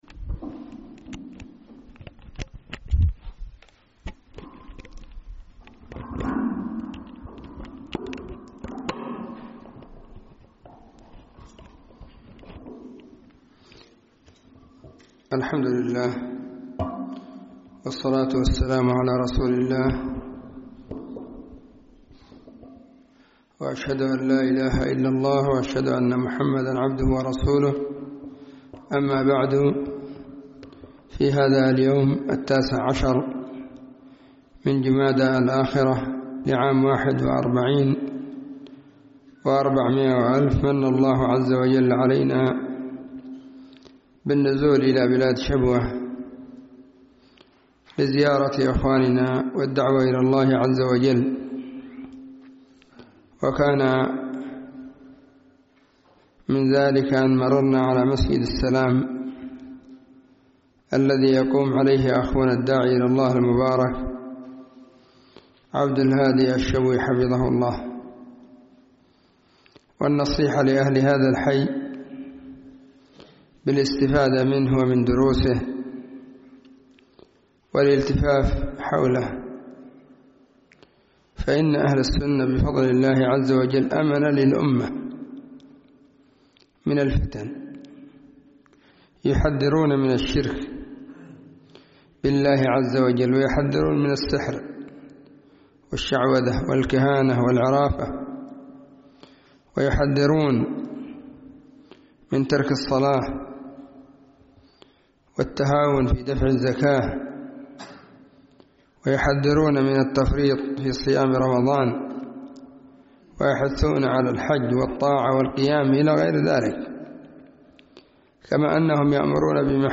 ألقيت في مسجد السلام بمدينة عتق محافظة شبوه